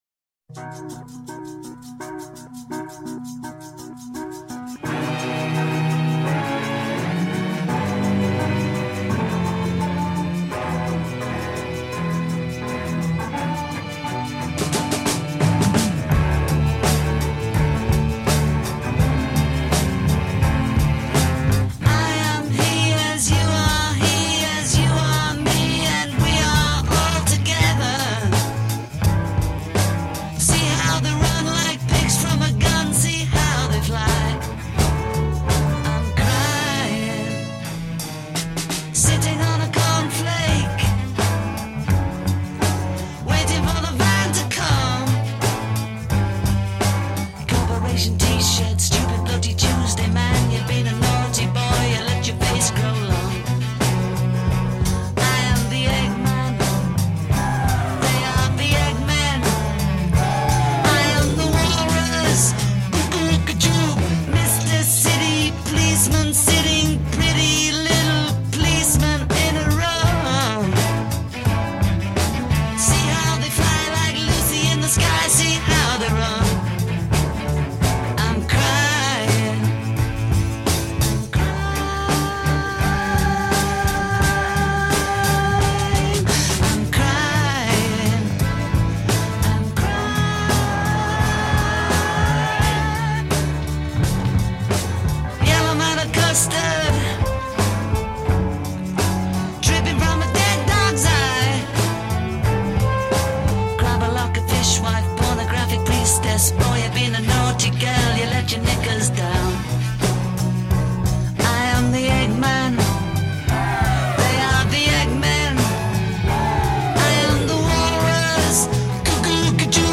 (ripped from my CDs!)